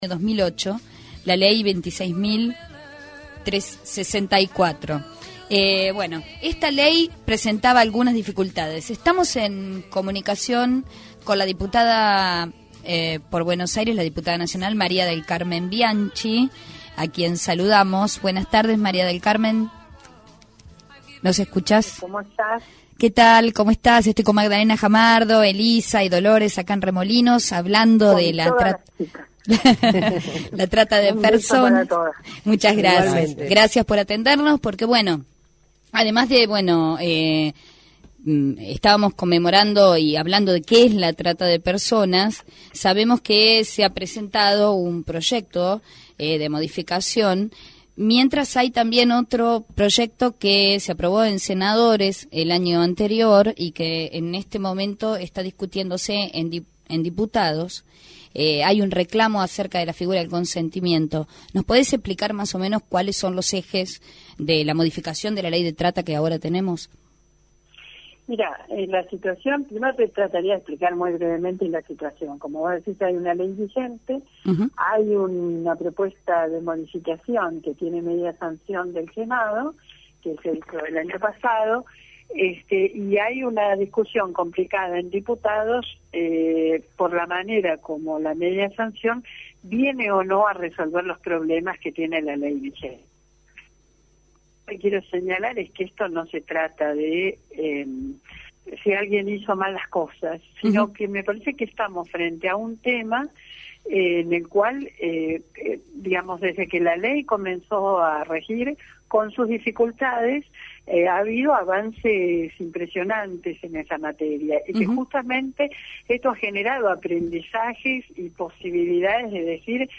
entrevista-Dip-Nac-MCBianchi.mp3